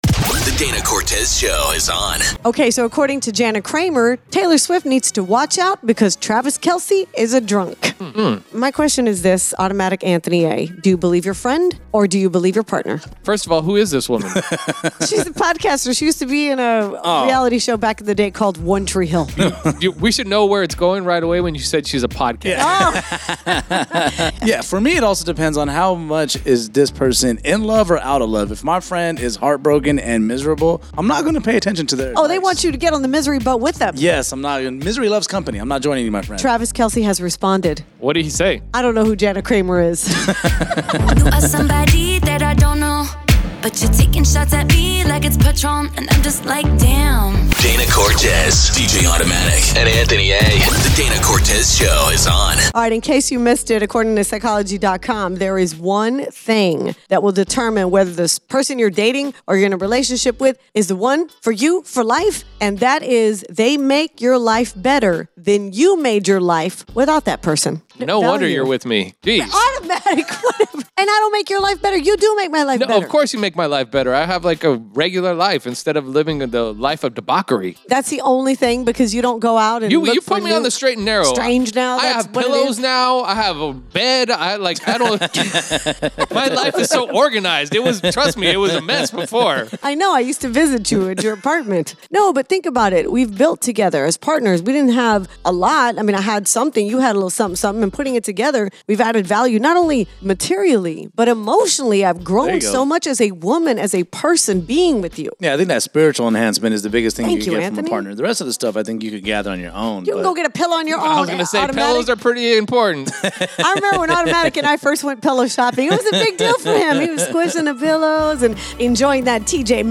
FEATURES A MARRIED COUPLE
AND THEIR SINGLE BEST FRIEND